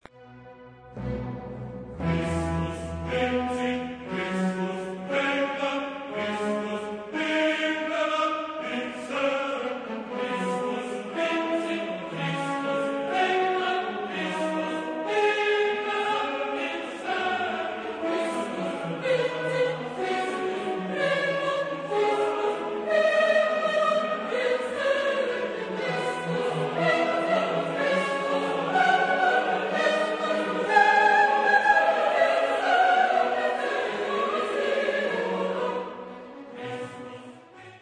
quintes impérieuses, conclut tout de même dignement un ouvrage en tous points enthousiasmant et exaltant.